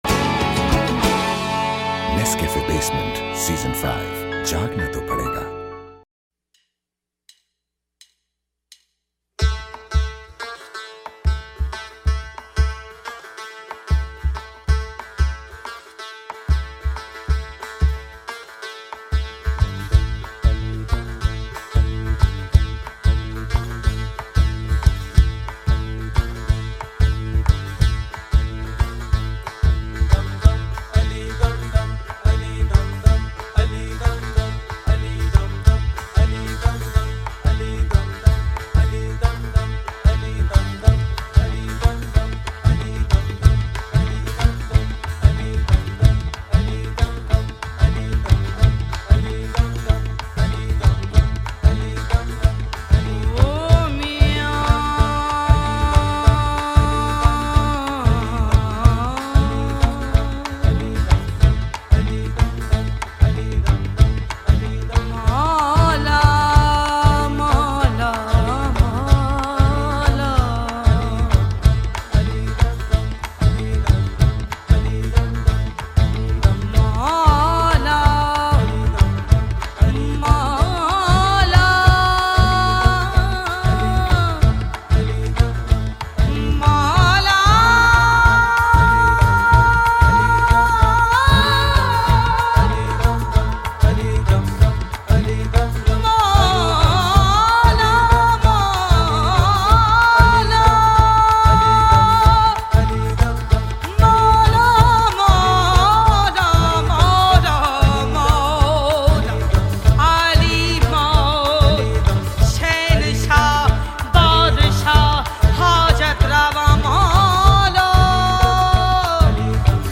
Sufi Songs